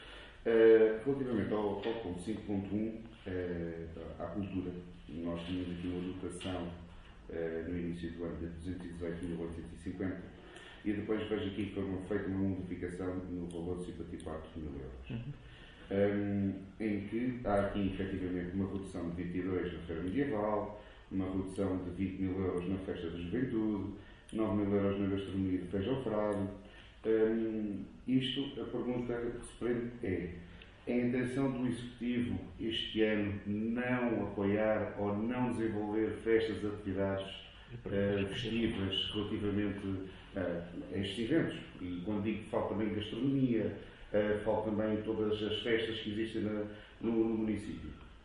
ÁUDIO | VEREADOR DO PSD, VITOR FILIPE: